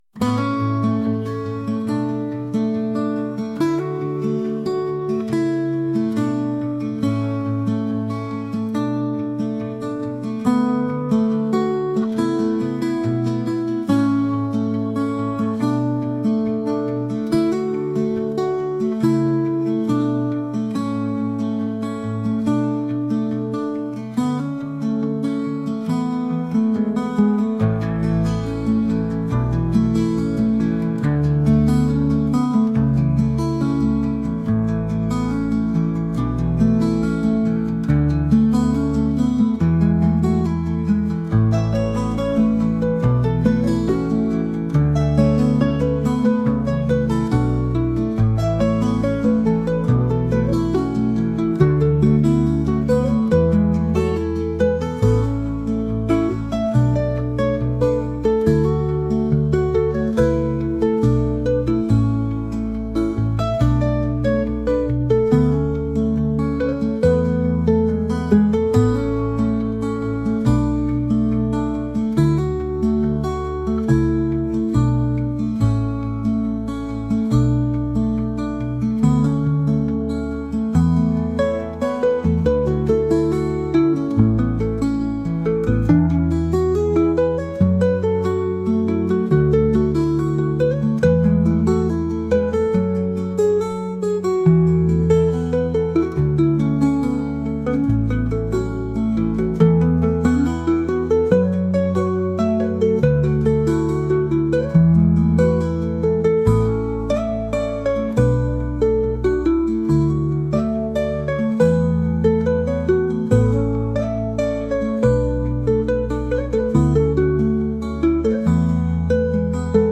ギターの素朴な味わいを醸し出すカントリー曲です。